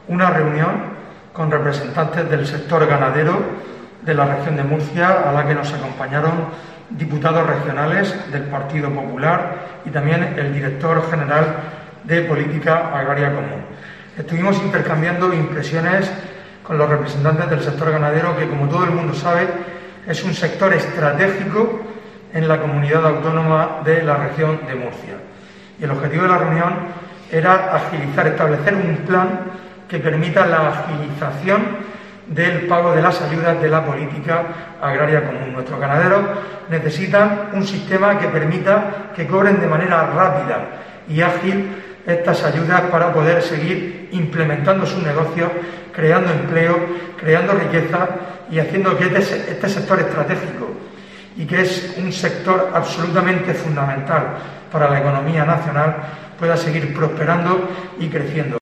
Fulgencio Gil, portavoz del PP de Lorca sobre ganaderos